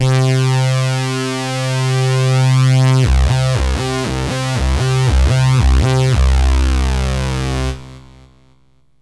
SYNTH LEADS-2 0004.wav